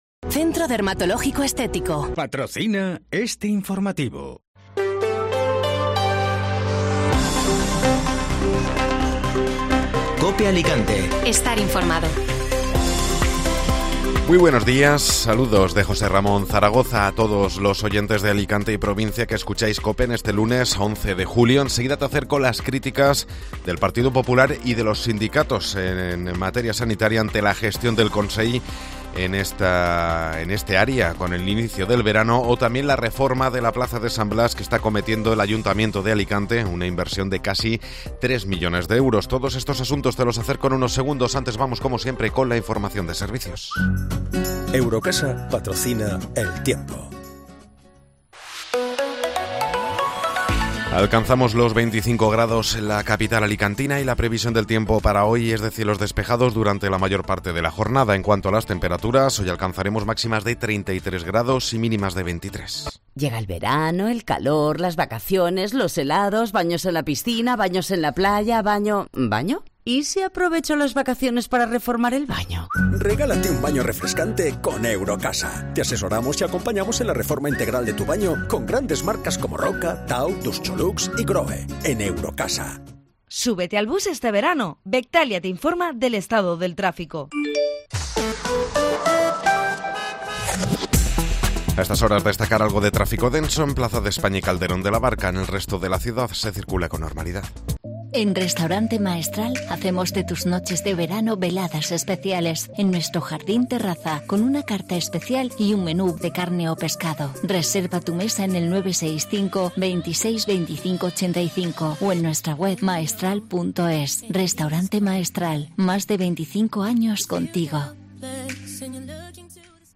Informativo Matinal (Lunes 11 de Julio)